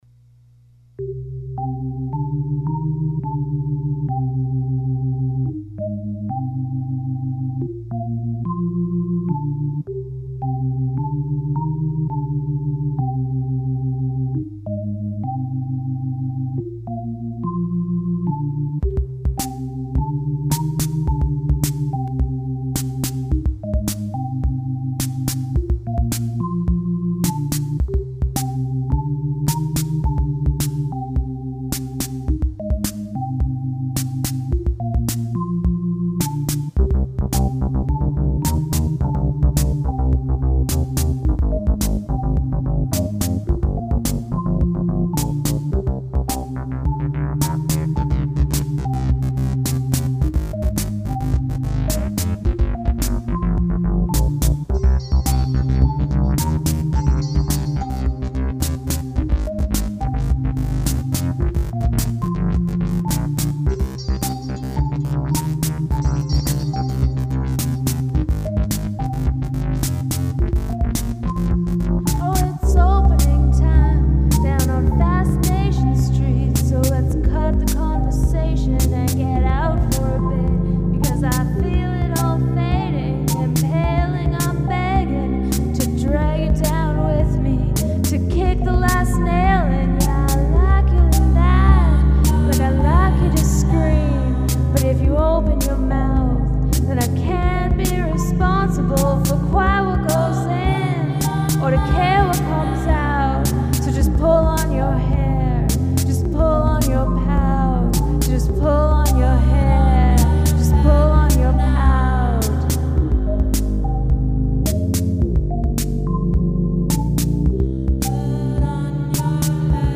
Our interpretation sounds really fricken good!